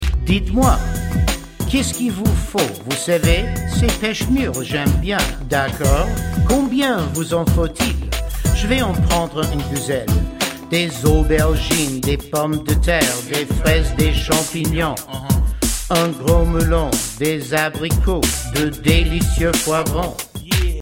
French Raps Song Lyrics and Sound Clip